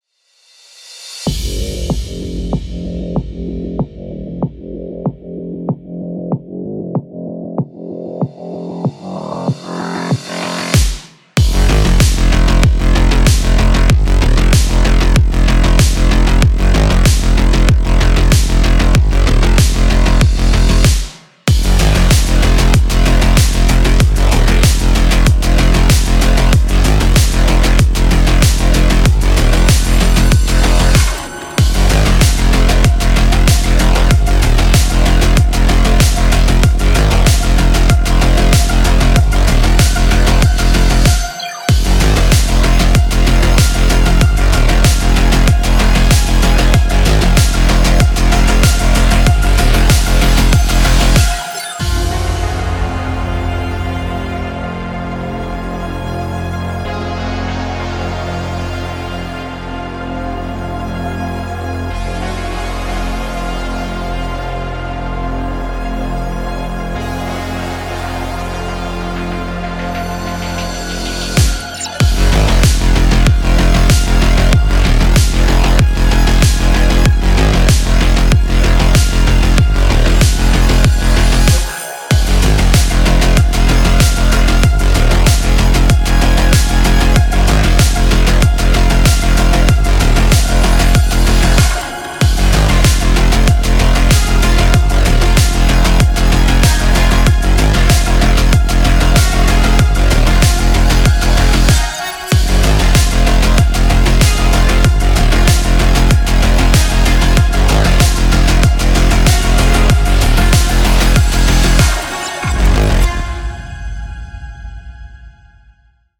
Genre: electronichardcore.